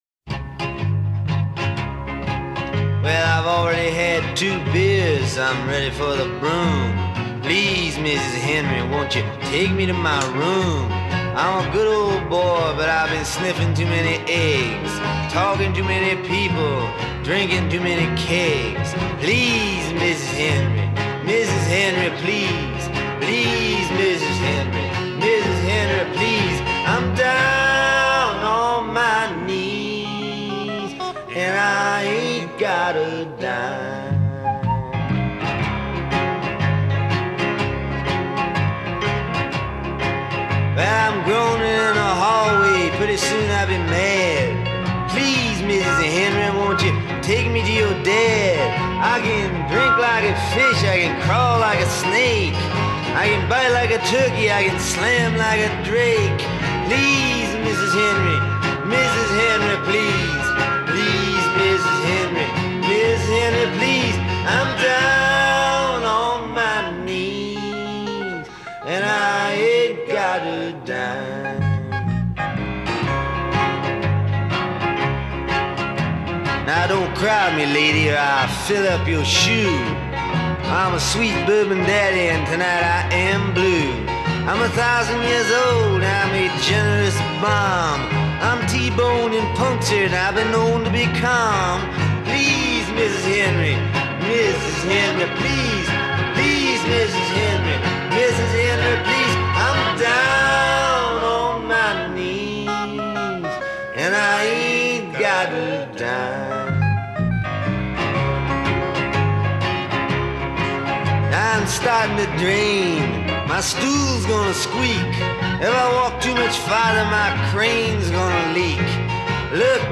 and overdubbed new instrumentation onto most of them.
a splendid example of the sort of drunken absurdity